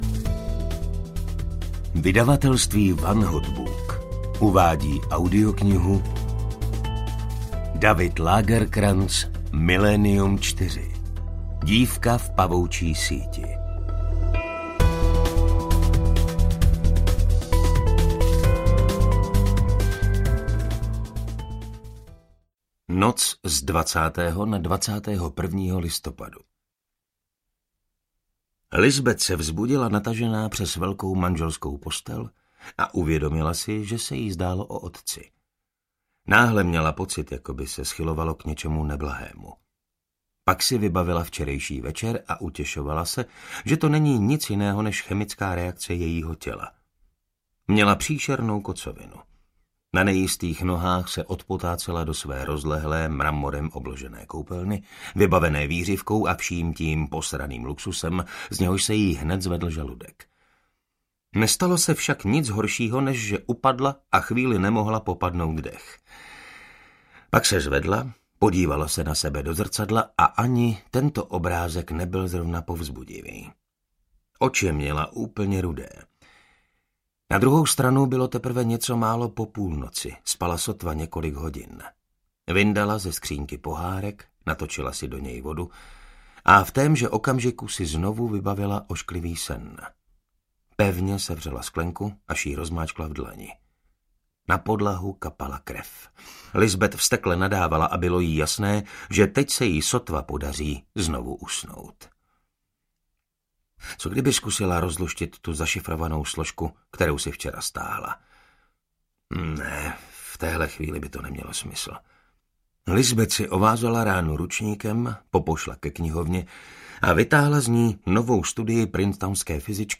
Dívka v pavoučí síti - Milénium IV audiokniha
Ukázka z knihy